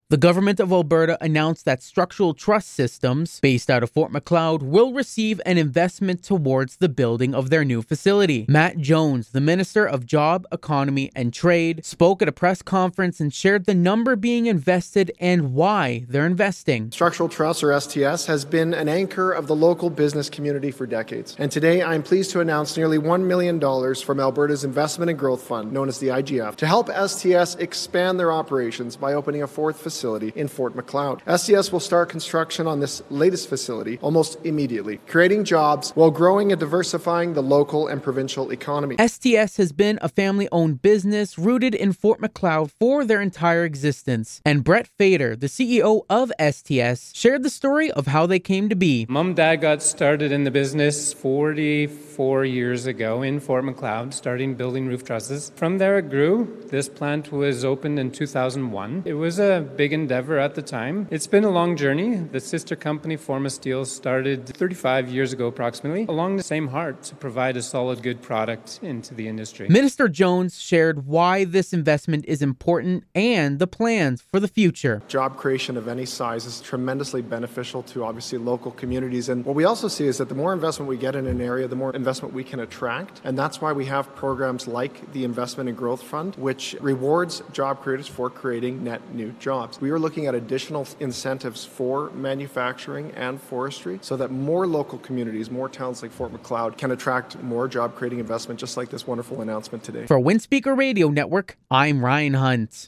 Matt Jones, the Minister of Jobs, Economy and Trade, spoke in a press conference from one of STS' three production facilities in Fort Macleod, mentioning that construction of their fourth and newest facility will start "almost immediately".